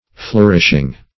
flourishing - definition of flourishing - synonyms, pronunciation, spelling from Free Dictionary